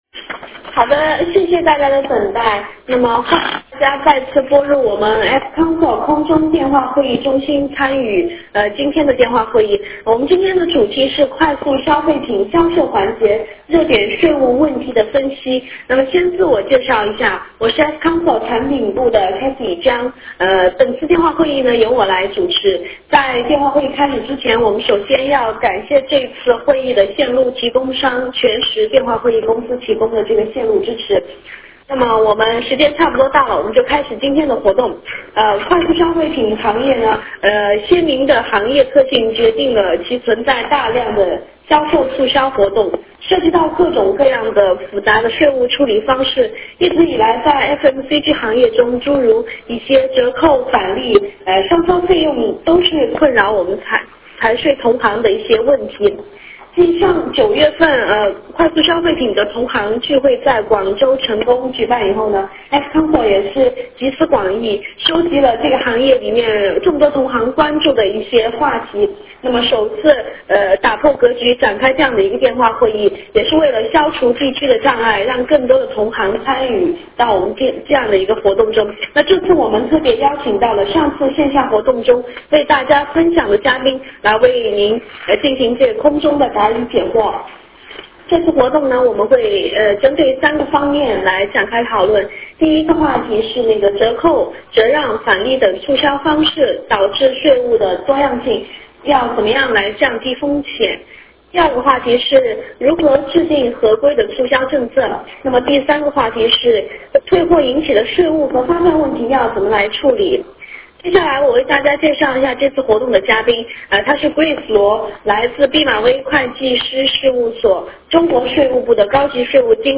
电话会议